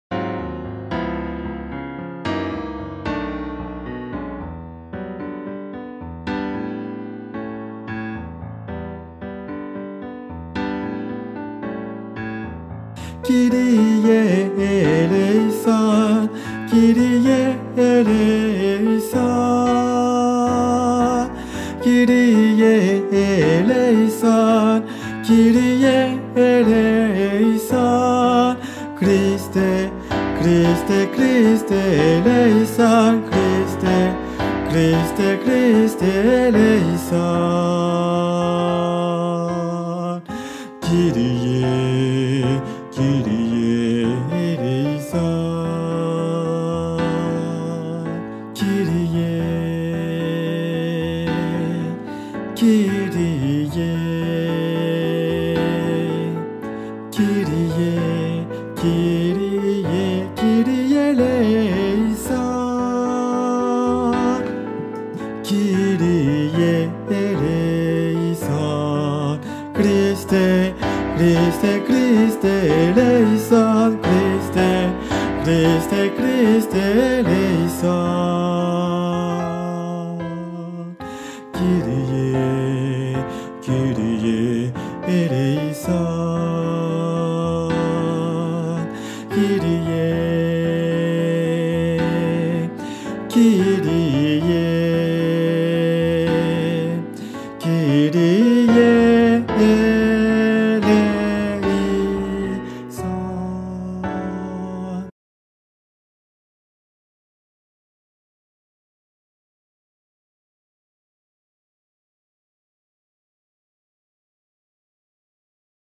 MP3 versions chantées
Hommes